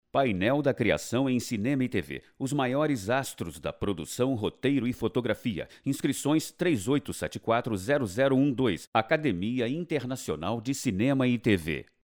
Masculino
Locução - Painel de Cinema e TV
Voz Varejo